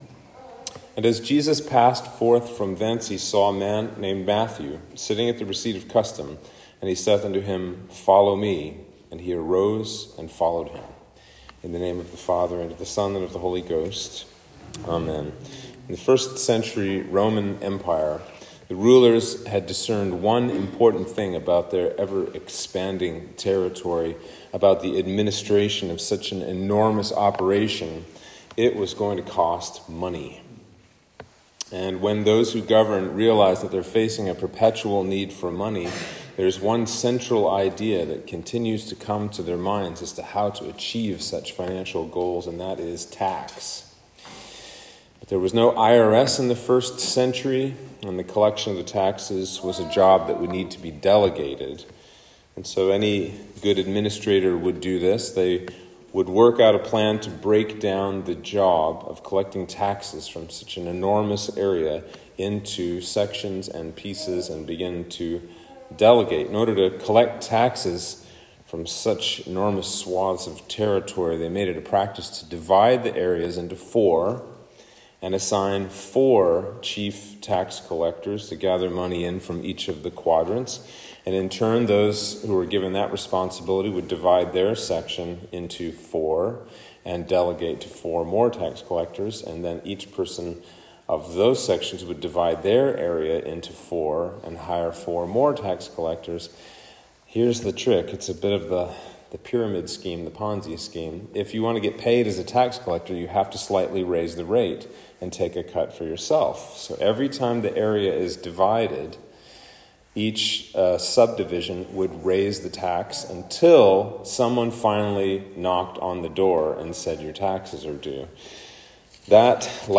Sermon for St. Matthew's Day